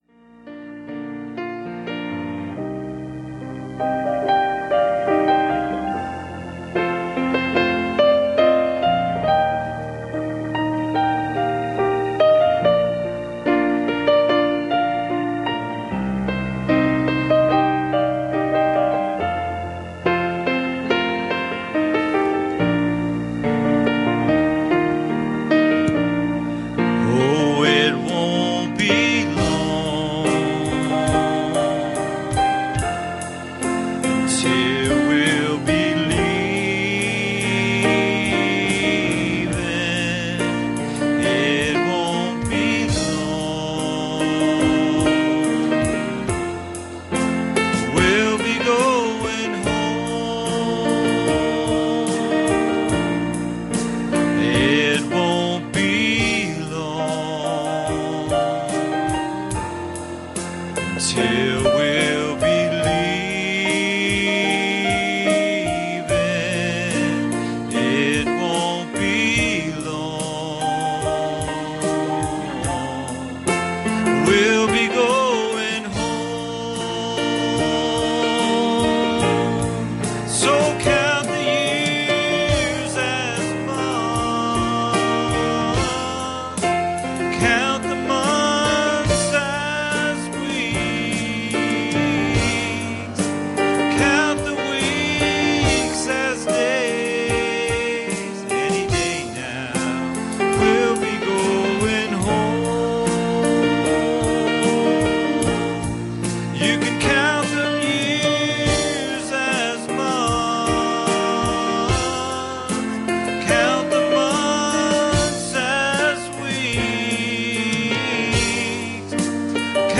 Series: Wednesday Evening Services
Service Type: Wednesday Evening